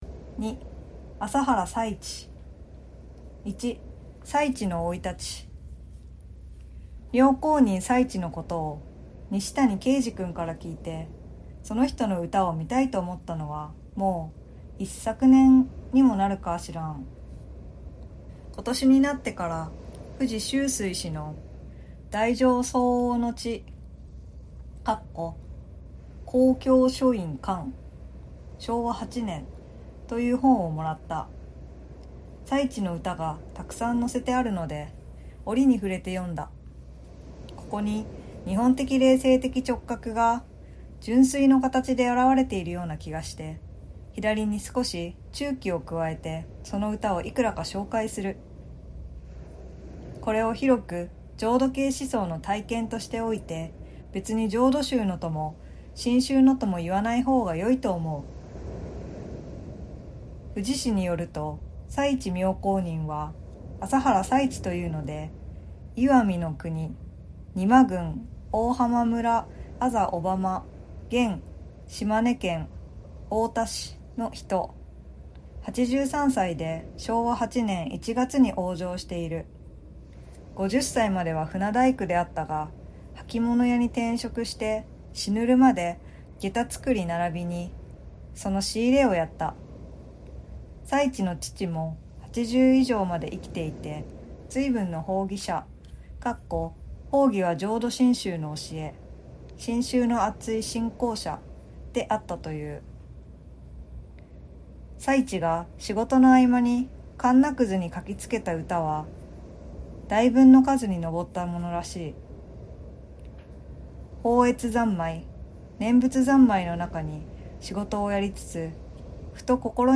心を豊かにする朗読。